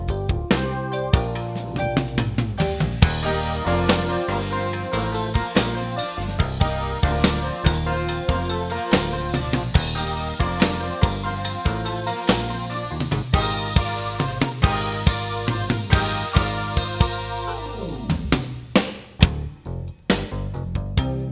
Figure 1 – A passage of raw (uncompressed) music. The crest factor is about 20 dB.
Wave File 1 – Unclipped music.
raw_waveform.wav